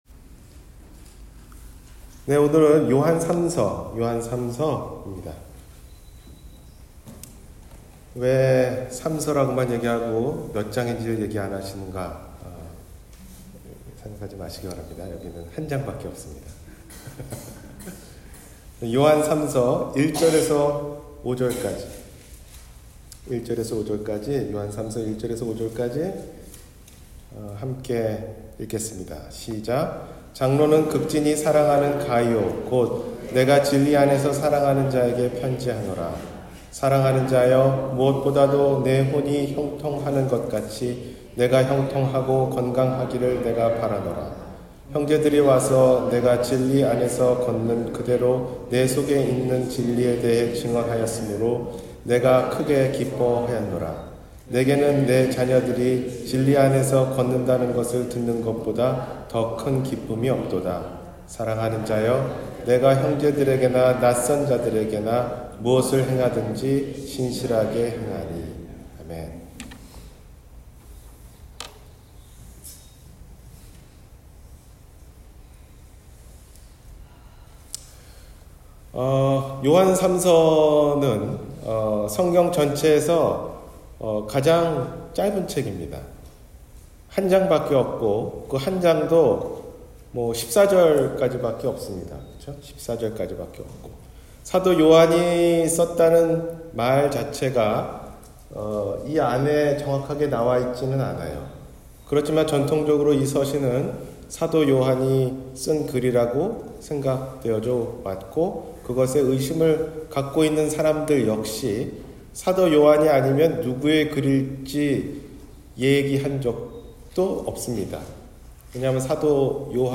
사랑 받을만한 사람 – 주일설교